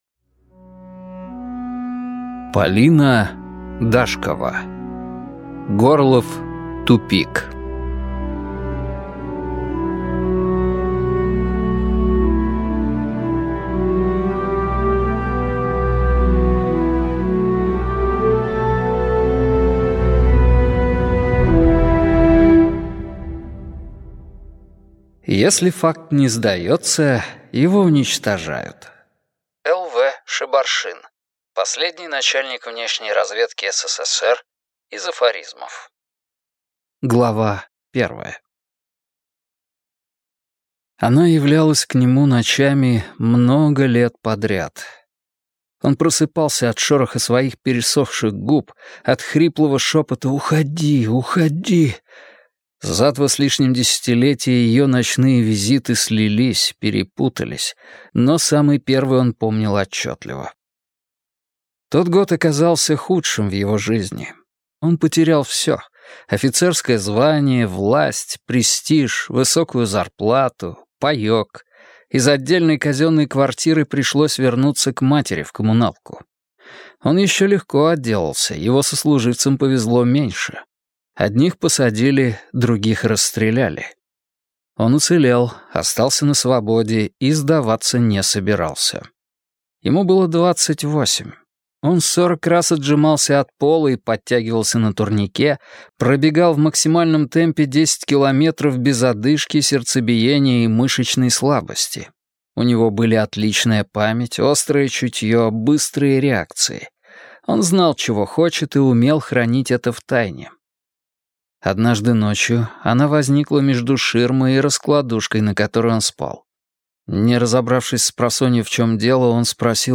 Аудиокнига Горлов тупик - купить, скачать и слушать онлайн | КнигоПоиск